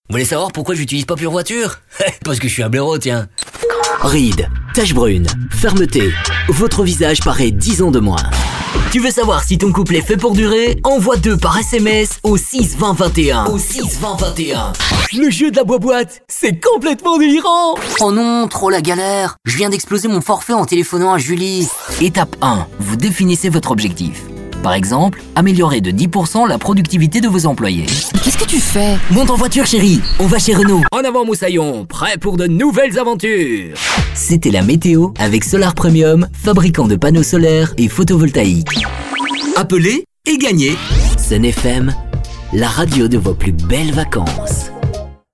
Sprechprobe: Sonstiges (Muttersprache):
Stunning medium voice. Young and dynamic